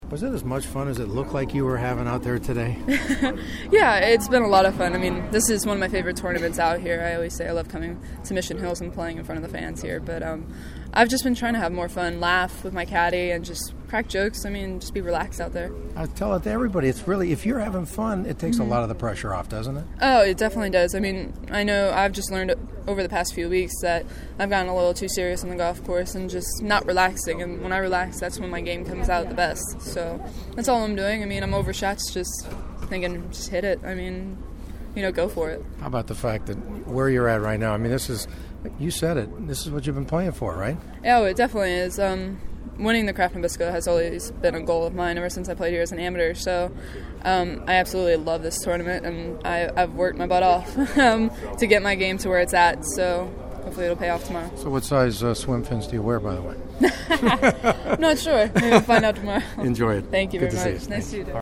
After Lexi’s 3 under par 68, she graciously gave me a few minutes to react to the possibility of her first major championship on her favorite course on tour.